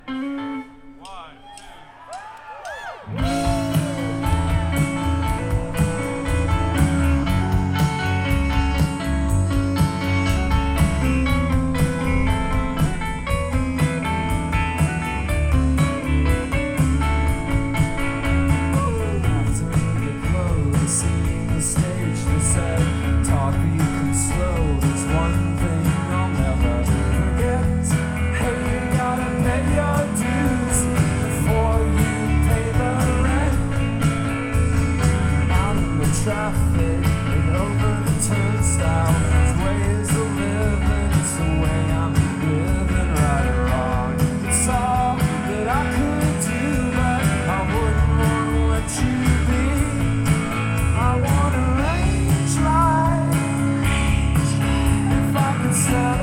piena di cori